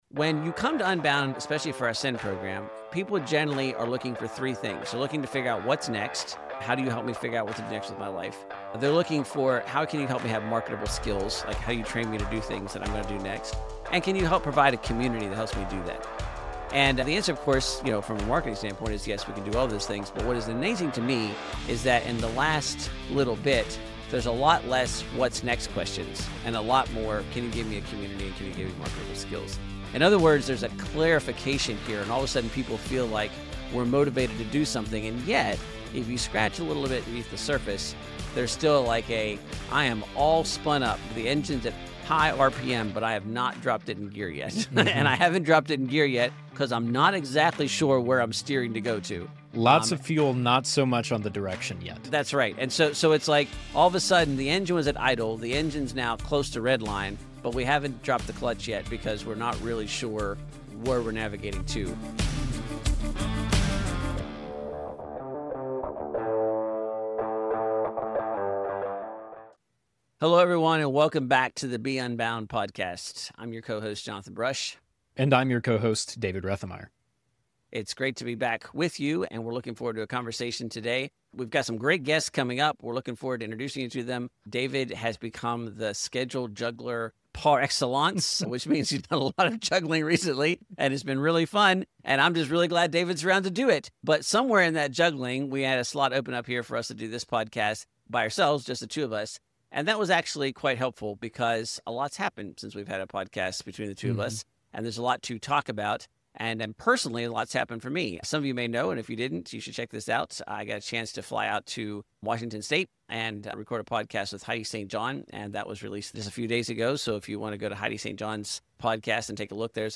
This is a grounding, hope-filled conversation that reminds us that eternal impact doesn’t always look like changing the world.